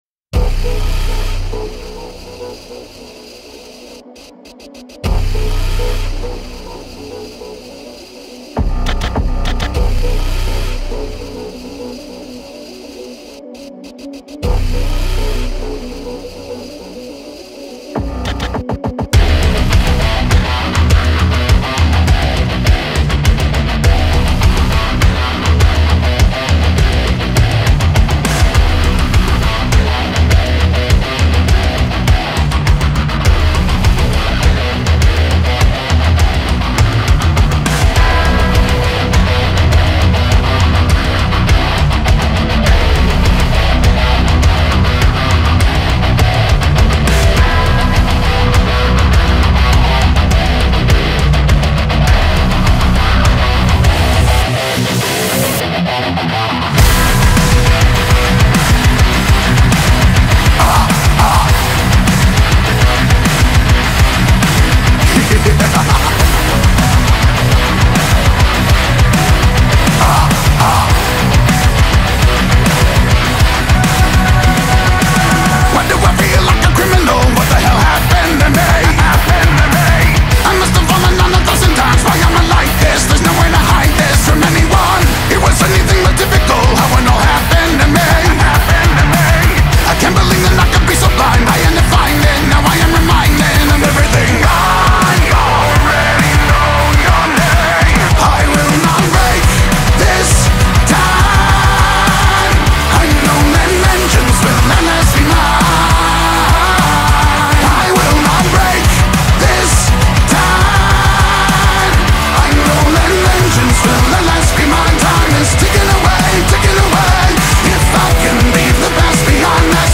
اونا یک گروه هِوی متال آمریکایی هستن